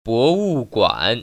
博物馆[bówùguǎn]